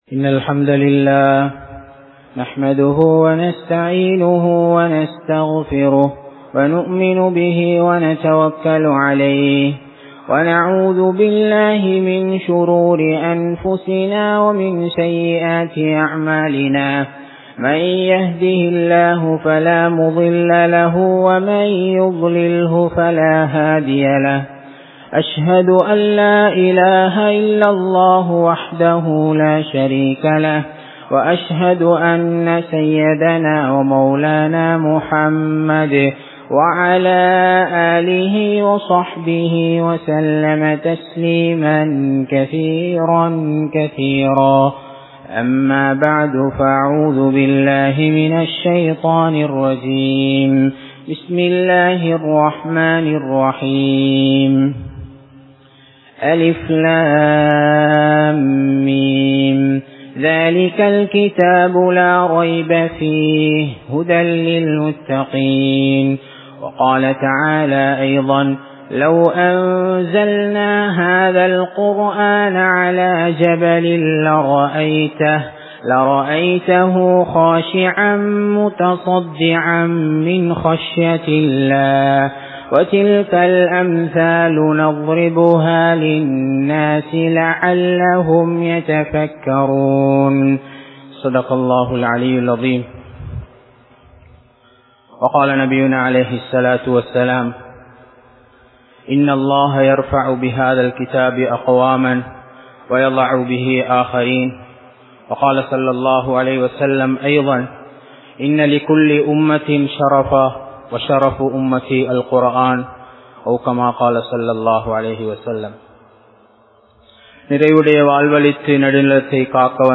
அல்குர்ஆனின் மகிமை | Audio Bayans | All Ceylon Muslim Youth Community | Addalaichenai
Muhiyaddeen Grand Jumua Masjith